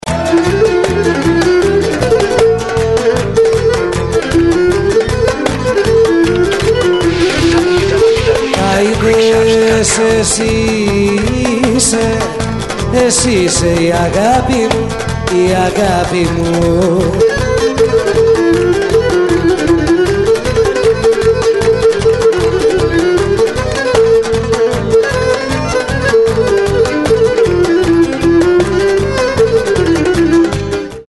A live album
featuring 22 great traditional-style Greek songs!